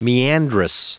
Prononciation du mot meandrous en anglais (fichier audio)
Prononciation du mot : meandrous